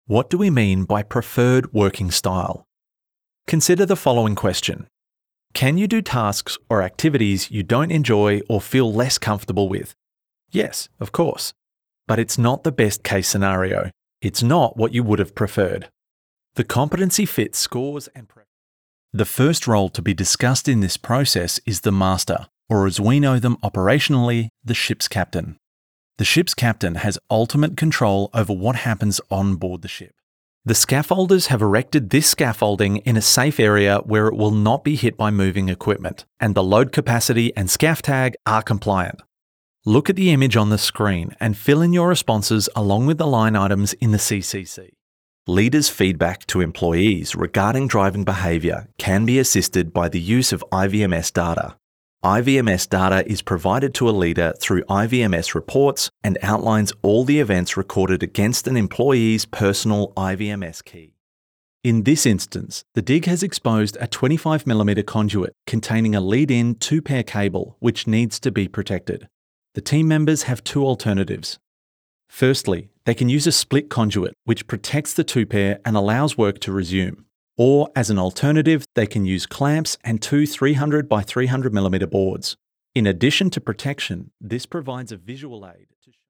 外籍男111-在线教育.mp3